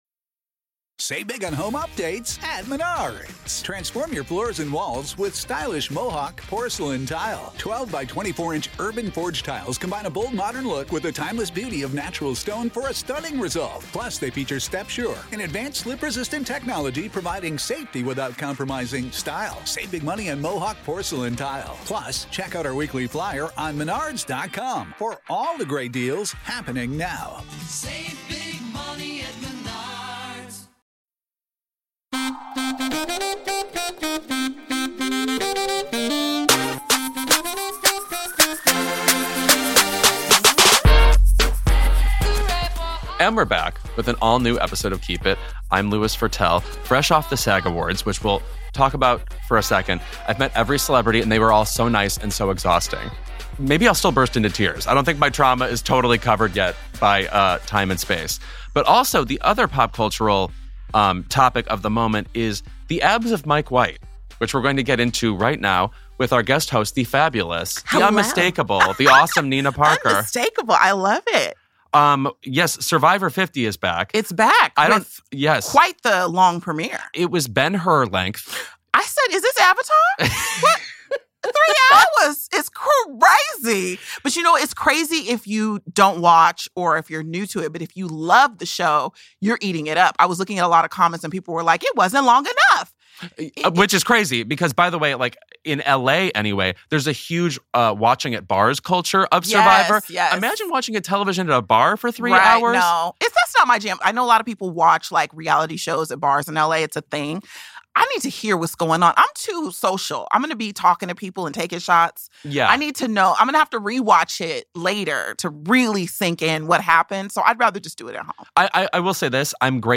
This week, Louis Virtel is joined by pop culture commentator Nina Parker to discuss the premiere of Survivor season 50 along with new music from Bruno Mars and Hillary Duff. Nina weighs in on the BAFTAs and the BBC's lackluster apology and Louis chats with Stellan Skarsgård about his Golden-Globe-winning and Oscar-nominated film Sentimental Value.